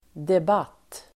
Uttal: [deb'at:]